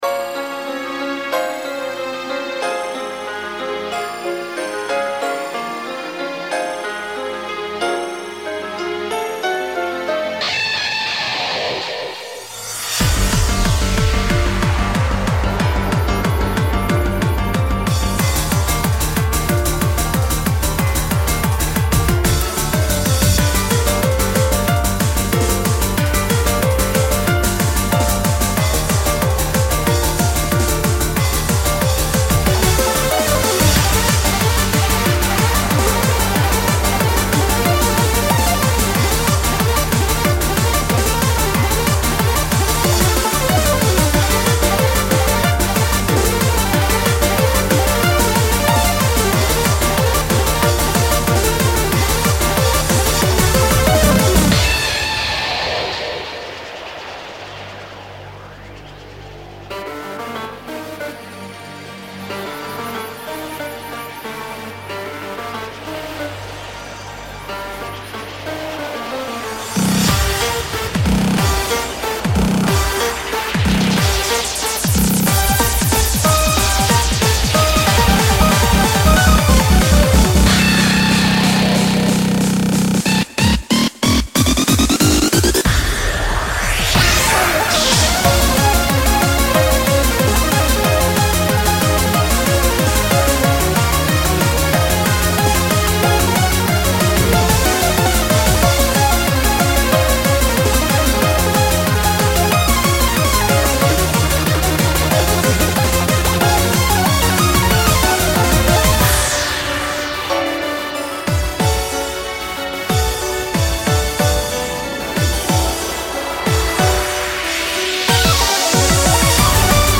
BPM185-185
Audio QualityPerfect (Low Quality)